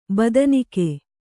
♪ badanike